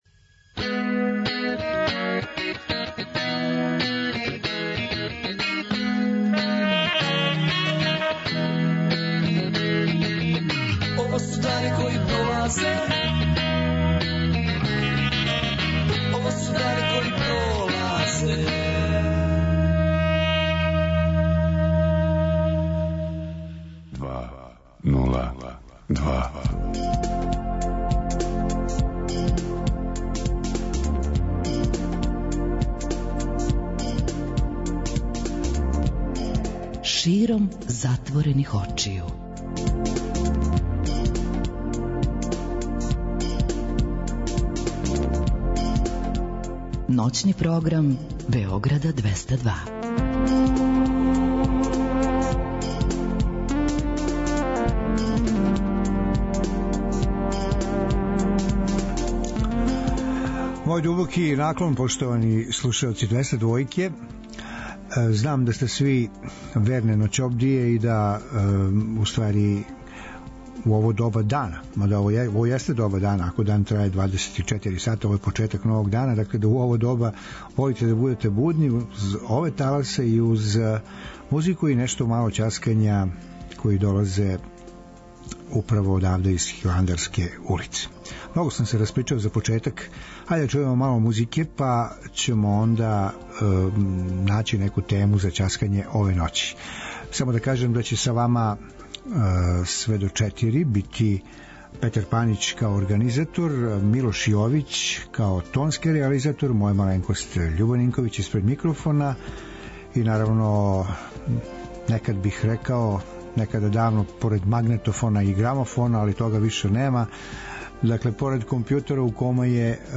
преузми : 41.95 MB Широм затворених очију Autor: Београд 202 Ноћни програм Београда 202 [ детаљније ] Све епизоде серијала Београд 202 Блузологија Свака песма носи своју причу Летње кулирање Осамдесете заувек!